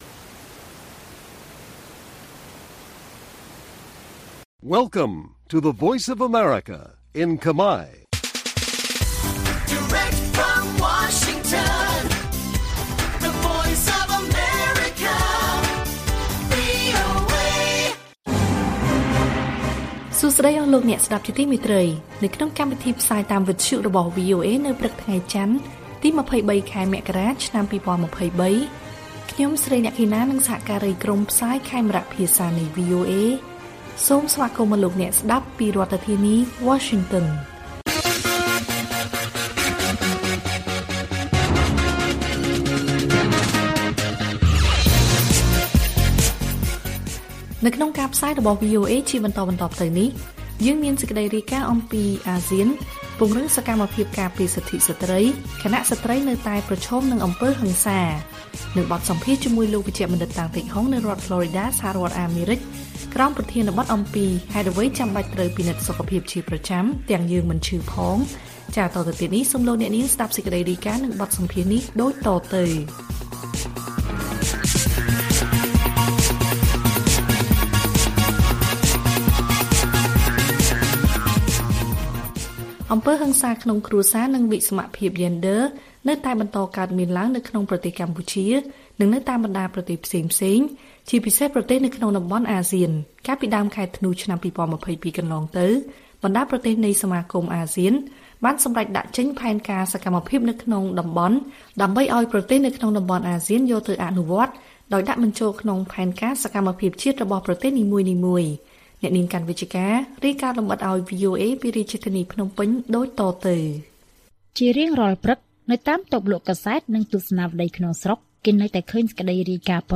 ព័ត៌មានពេលព្រឹក